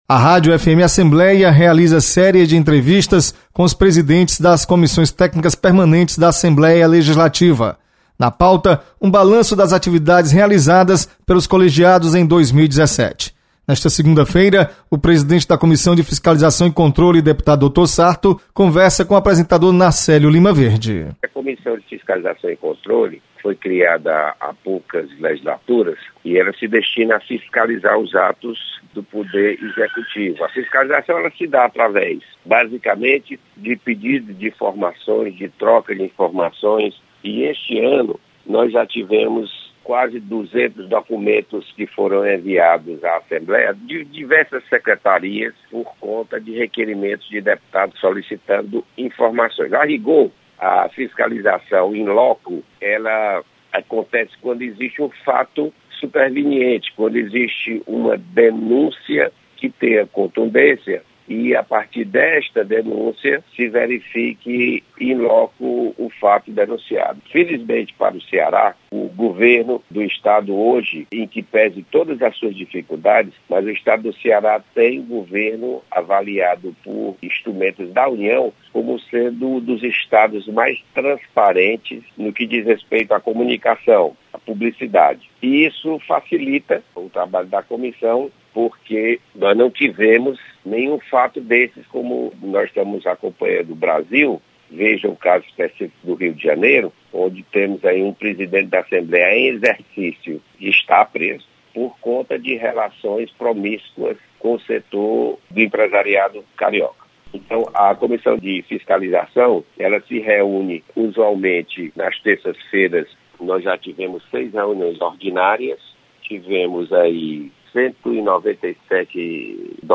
Deputado Dr. Sarto faz balanço da Comissão de Fiscalização e Controle.